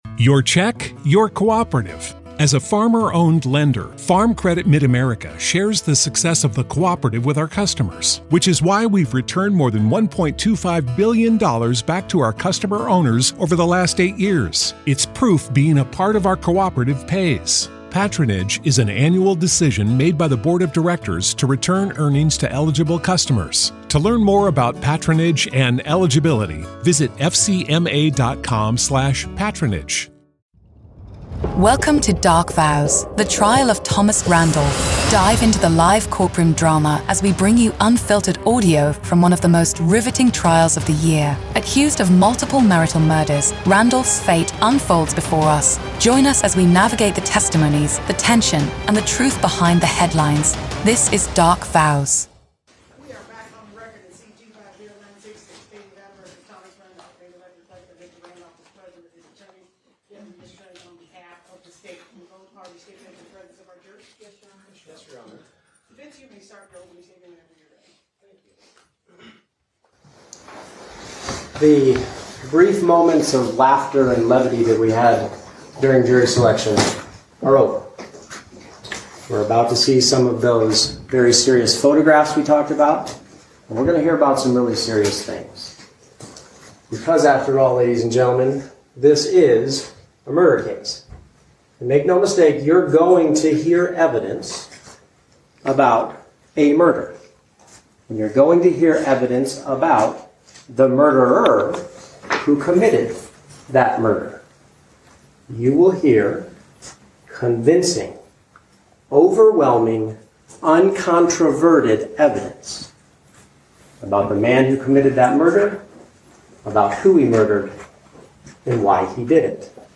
Every episode beams you directly into the heart of the courtroom with raw, unedited audio from testimonies, cross-examinations, and the ripple of murmurs from the gallery.
We accompany the live audio with expert legal insights, breaking down the day's events, the strategies in play, and the potential implications of each revelation.